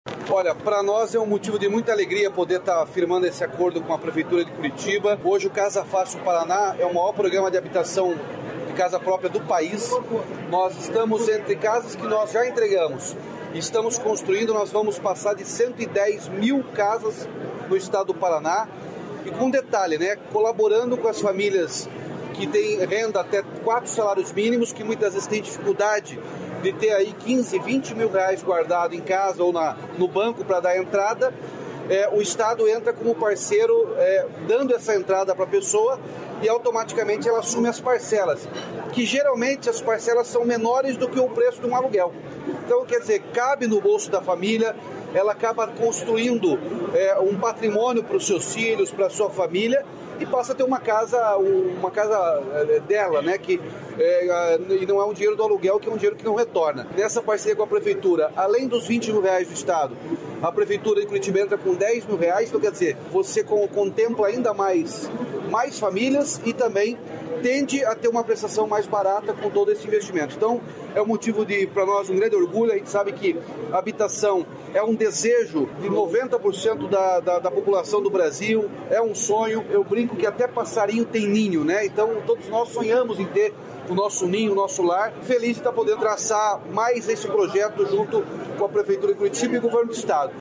Sonora do governador Ratinho Junior sobre o Casa Curitibana